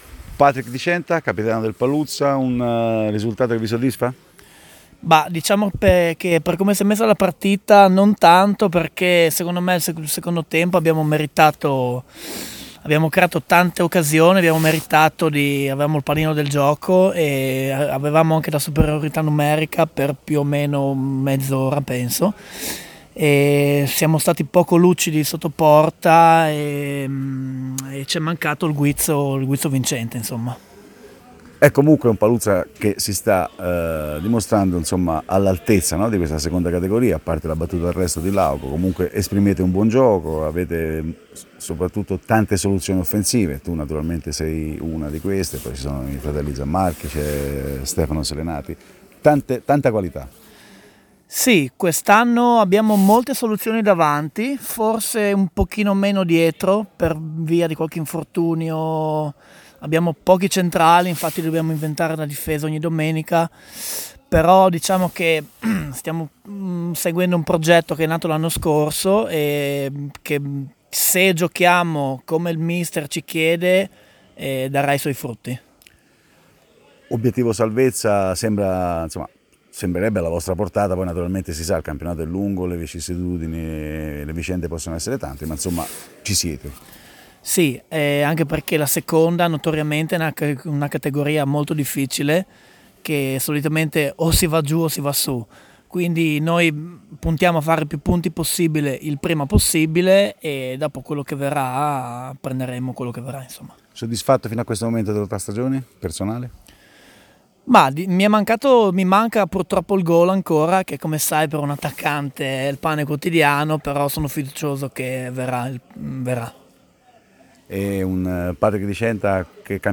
LE AUDIOINTERVISTE DI RAVASCLETTO-PALUZZA
Proponiamo le audiointerviste realizzate al termine di Ravascletto-Paluzza, conclusa sull’1-1.